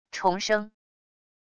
虫声wav音频